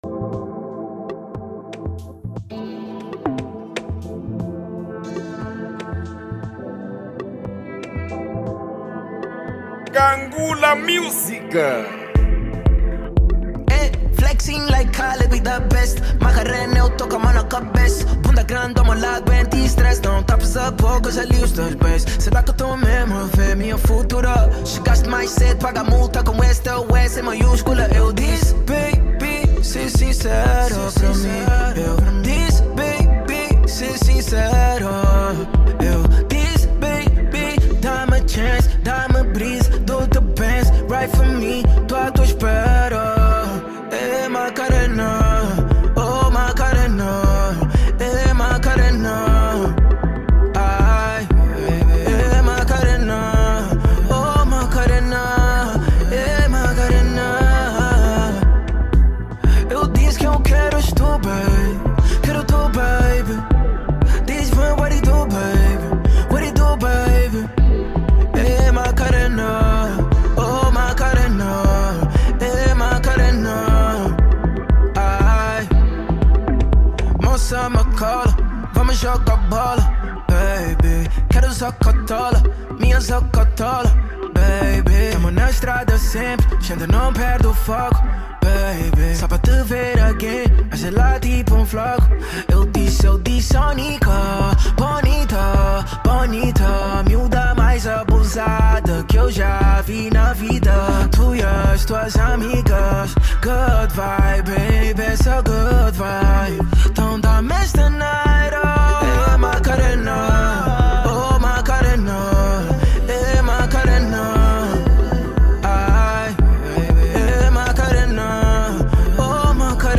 | Rap